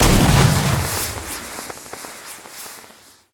firework
flair.ogg